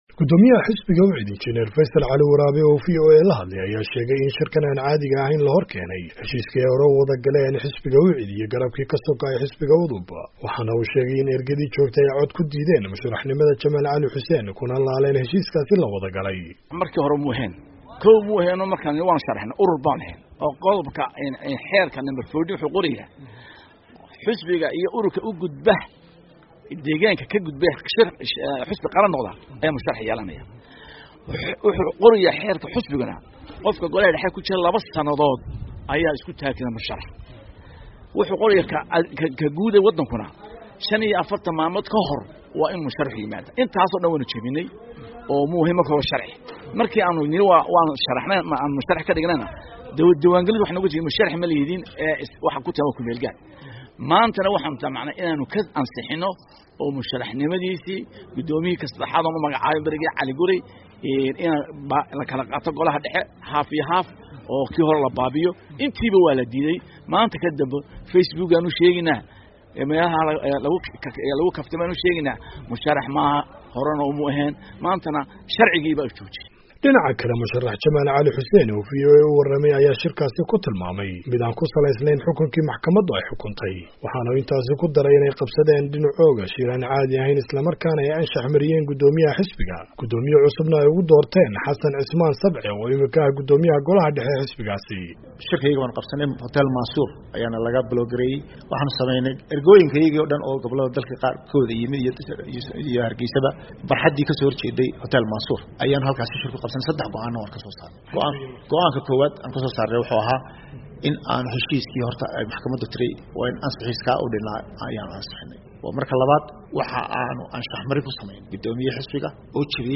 Warbixin: Go'aanada Xisbiga UCID